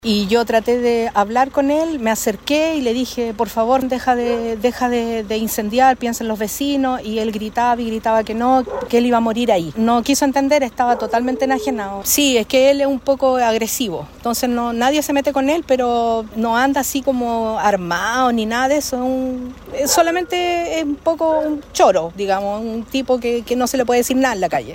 Una residente del lugar socorrió a la víctima e intentó dialogar con el hombre para que apagara el fuego. La vecina también comentó que esta persona era calificada como agresiva por el entorno.